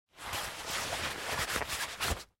Звуки кошелька
Шорох кошелька из кармана пальто